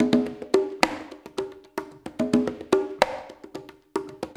APH CONGAS.wav